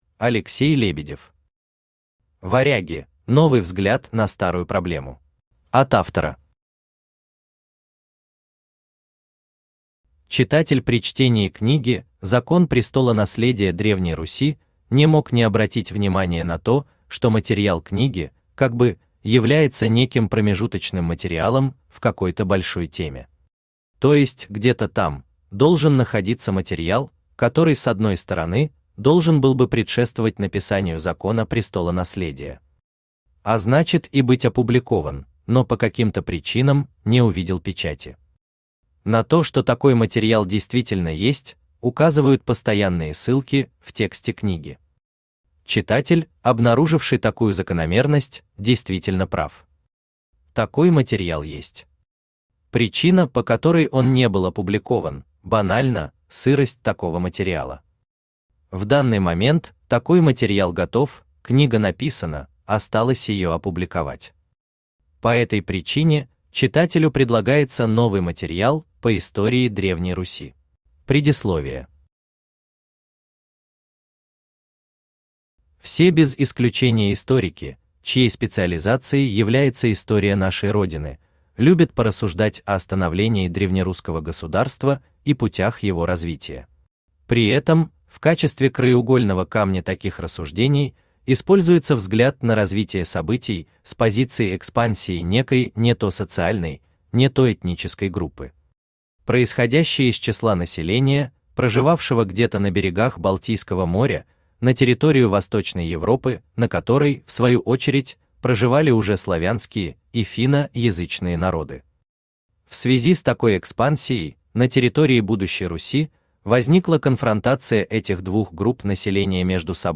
Аудиокнига Варяги: новый взгляд на старую проблему | Библиотека аудиокниг
Aудиокнига Варяги: новый взгляд на старую проблему Автор Алексей Николаевич Лебедев Читает аудиокнигу Авточтец ЛитРес.